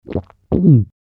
Royalty free sounds: Body sounds